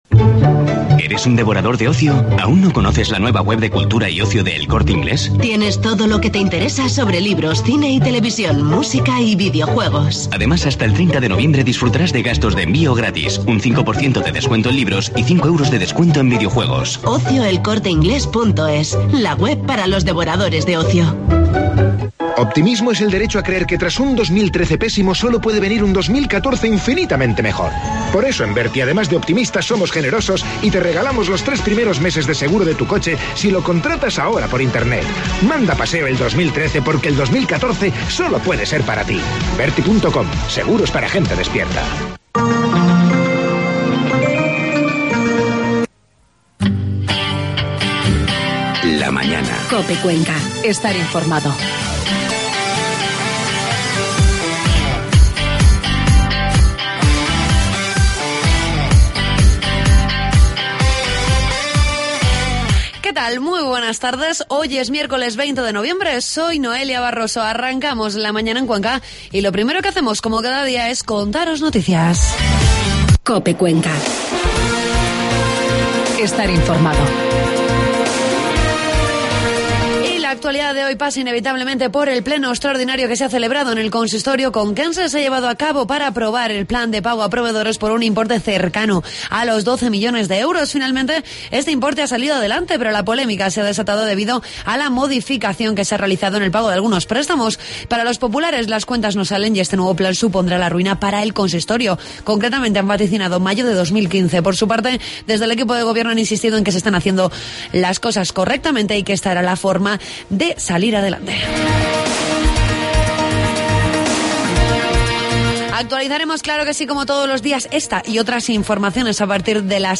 Entrevistamos al alcalde de Cuenca, Juan Ávila, con el que tratamos...